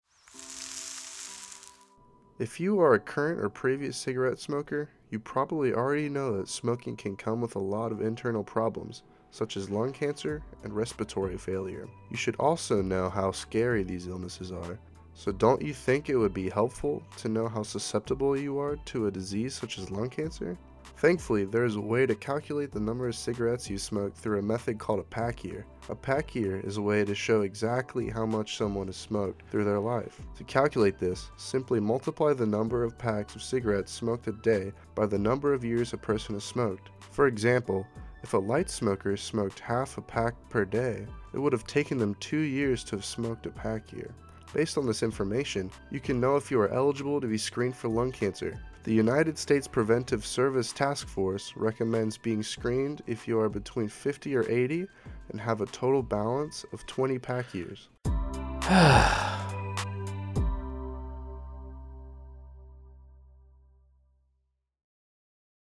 Radio PSAs